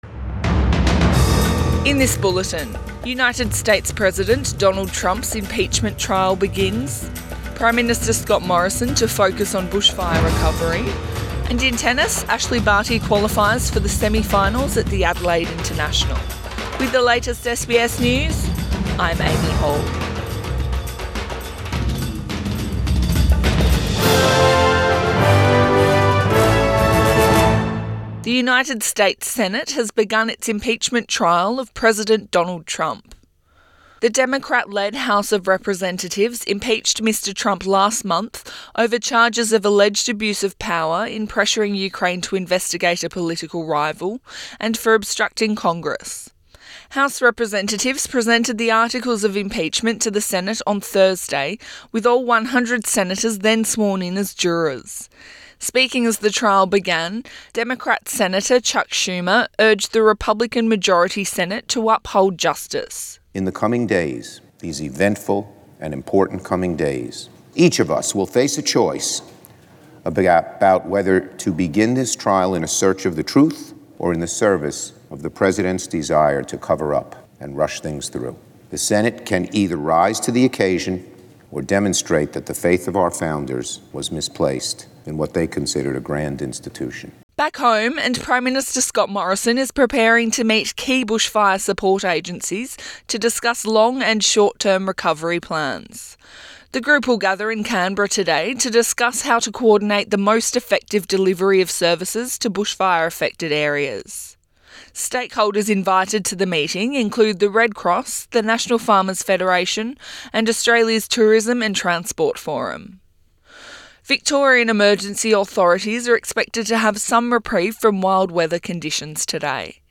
AM bulletin 17 January 2020